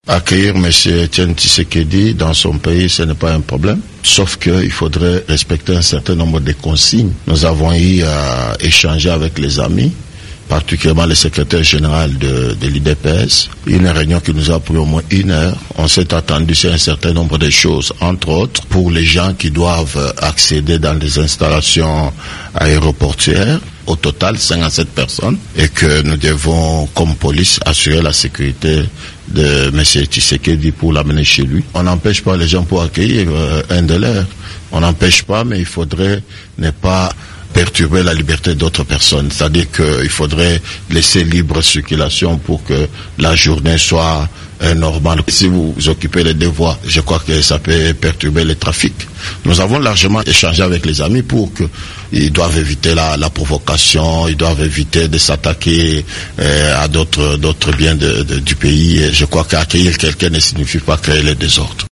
Le général Kanyama au micro de Top Congo FM